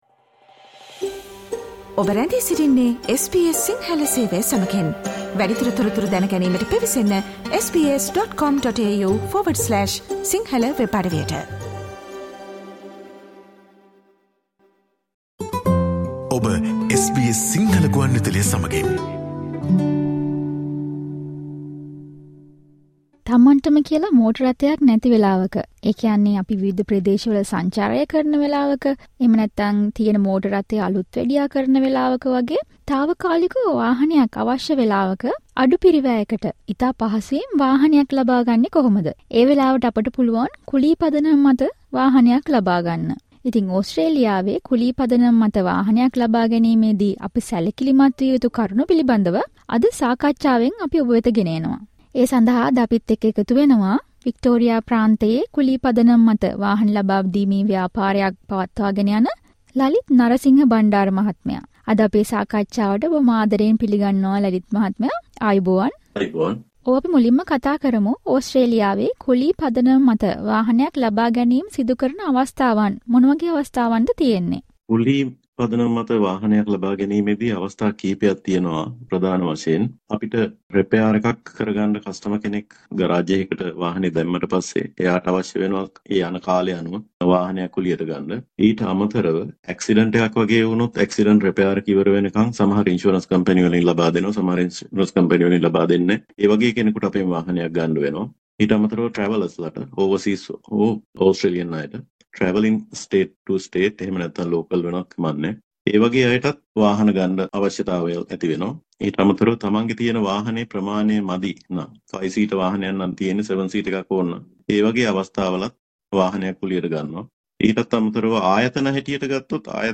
කුලි පදනම මත වාහනයක් ලබා ගන්නා විට ඔබ සැලකිලිමත් විය යුතු කරුණු පිලිබදව SBS සිංහල සේවය විසින් සිදුකල සාකච්චාවට සවන් දෙන්න.